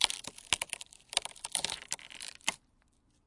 木材 " 破碎的木材
描述：慢慢地打碎一块木板，强调每一个小木头碎片的撕裂。
Tag: 流泪 吱吱声 碎片 木板 尖叫声 打破